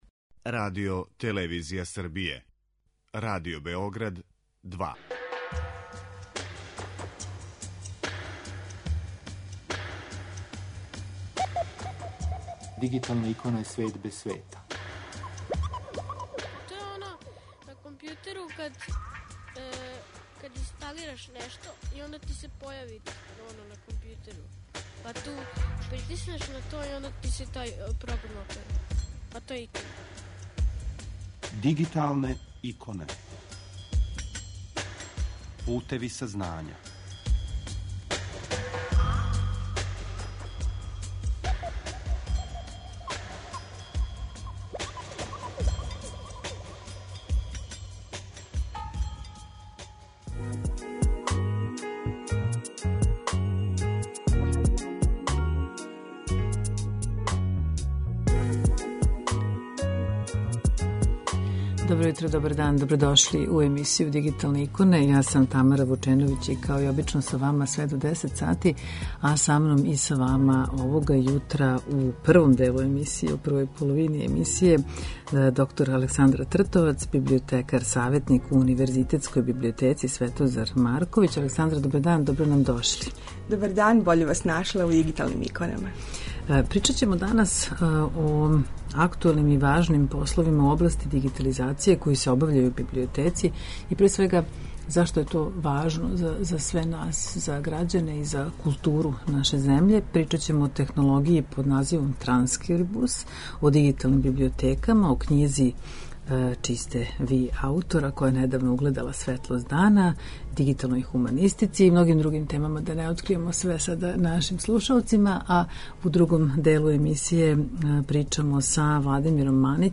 Емисија Дигиталне иконе покренута je 2002. године, а емитује се сваког уторка на таласима Радио Београда 2 од 9 до 10 сати.